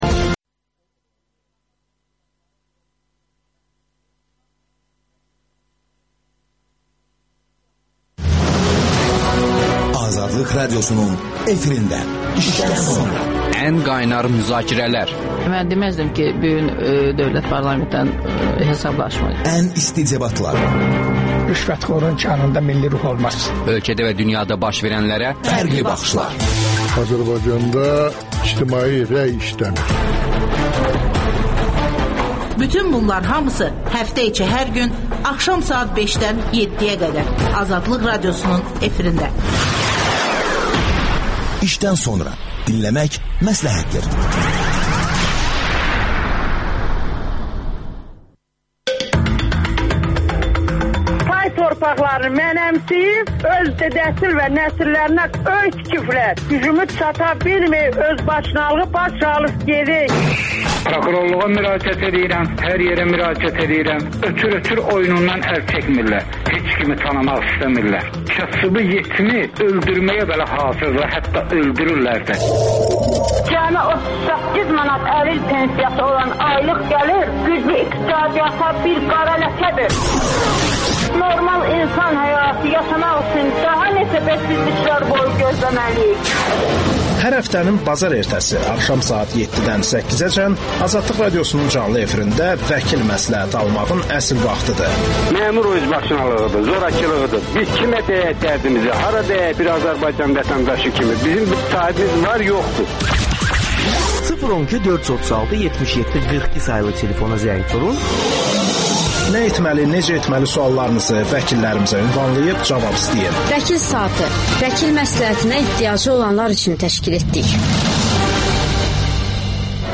İşdən sonra - Sülh və Demokratiya İnstitutunun rəhbəri Leyla Yunus canlı efirdə